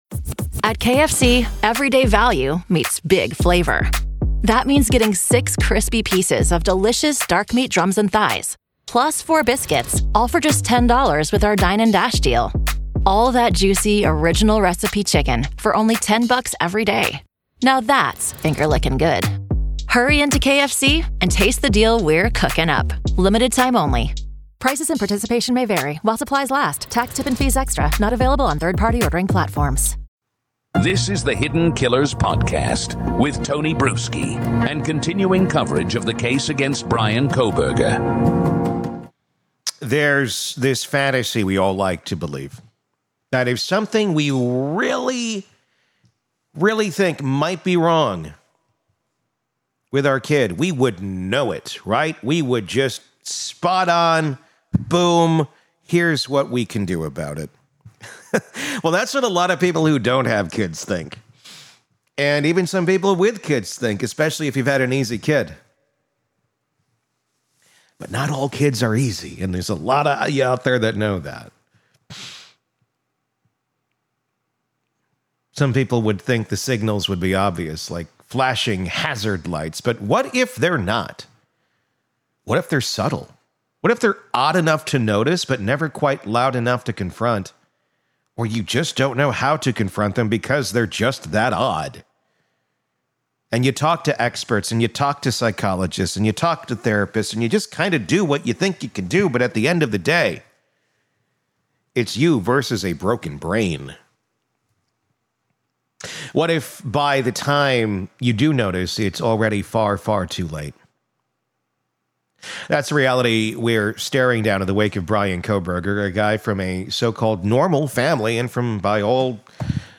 This conversation goes deep into the disturbing intersection of intellect, obsession, and pathology—and whether academia unwittingly gave Kohberger the tools to kill.